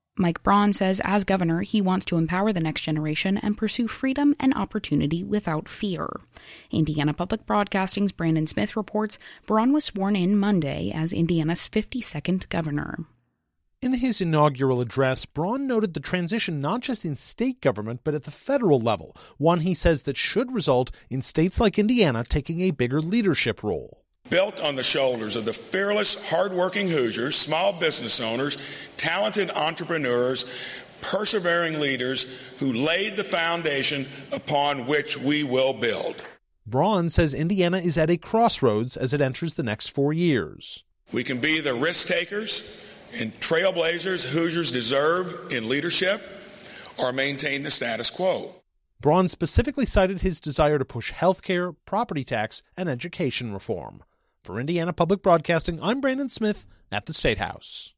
Mike Braun was sworn in as Indiana's 52nd governor on Jan. 13, 2025 at the Hilbert Circle Theatre in downtown Indianapolis.
mike-braun-inauguration.mp3